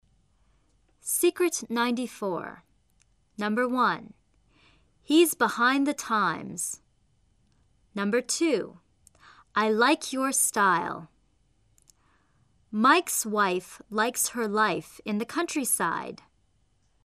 李阳美语发音秘诀MP3之秘诀94:合口双元音［aI］的发音技巧 听力文件下载—在线英语听力室